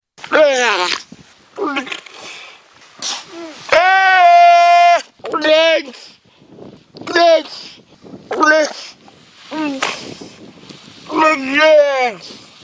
Категория: Различные звуковые реалтоны